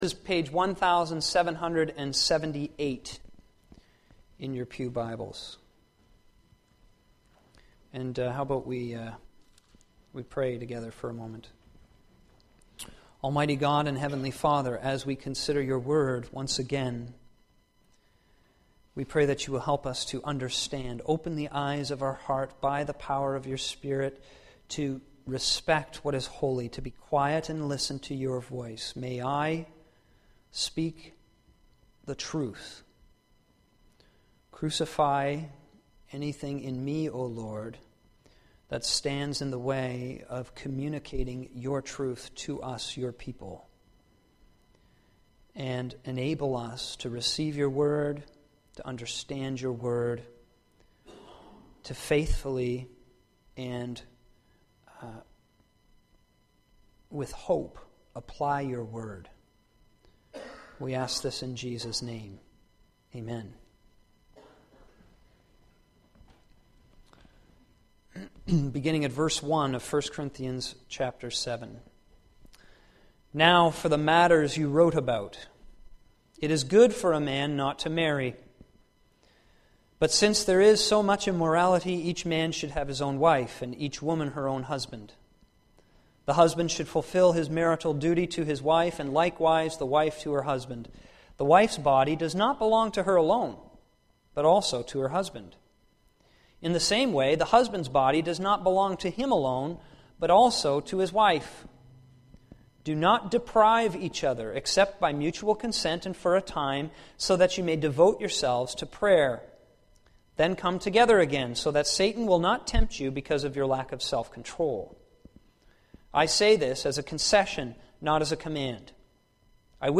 In the second of two sermons on divorce and remarriage, we will study Paul's teaching on the subject.